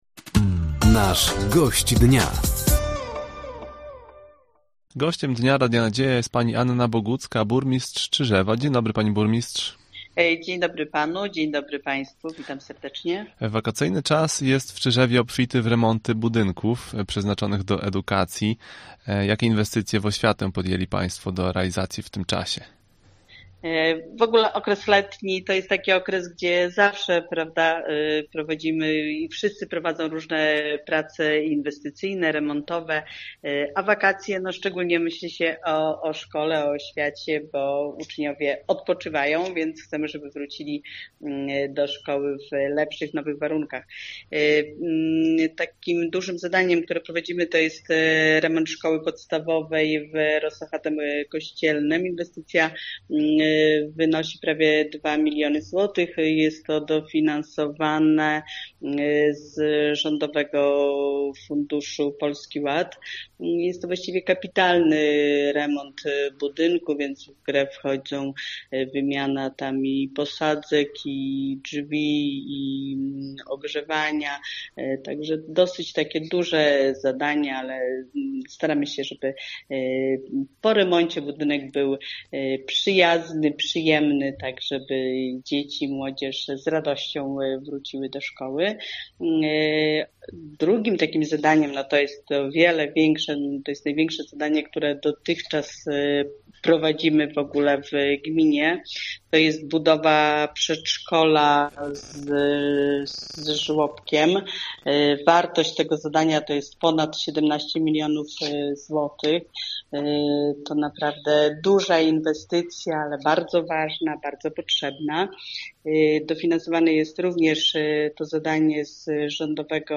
Gościem Dnia Radia Nadzieja była Anna Bogucka, Burmistrz Czyżewa. Tematem rozmowy były inwestycje związane z budynkami oświaty, willi generała Roszkowskiego i infrastrukturą drogową. Burmistrz wspomniała również o sprzedaży węgla w gminie.